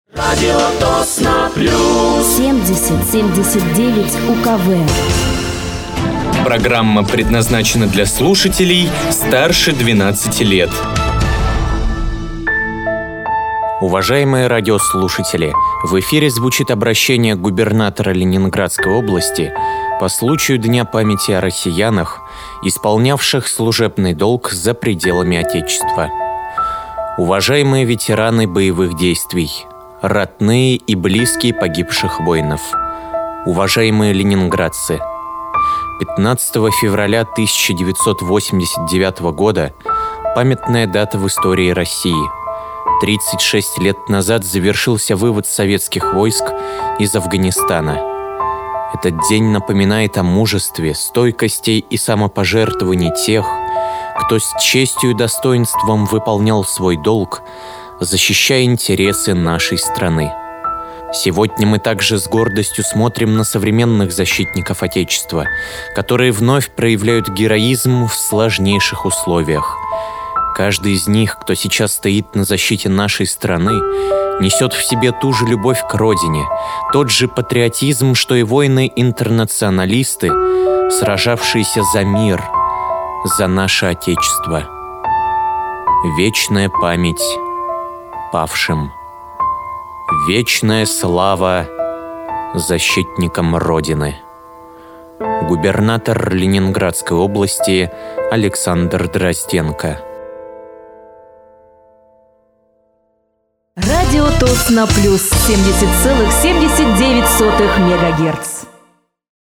Обращение Губернатора Ленинградской области